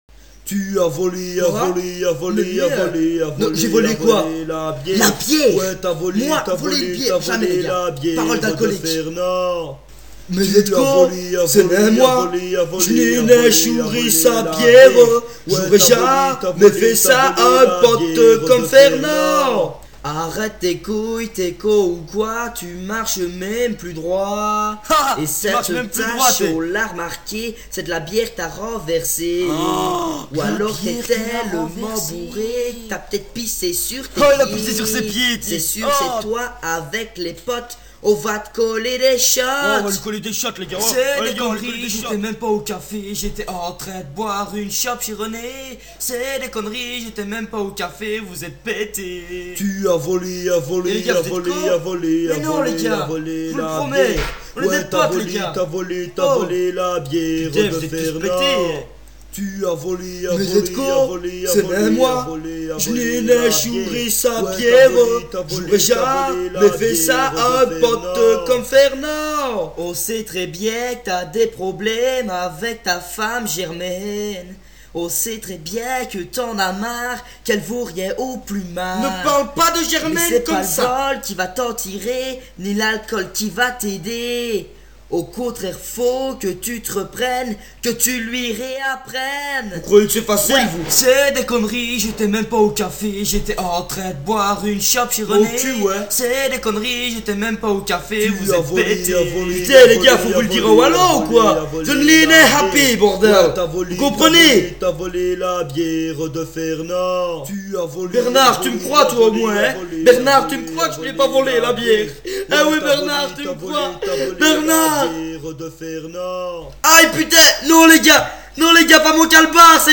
Voici qu'arrive pour vous la version Liègeoise de ce hit.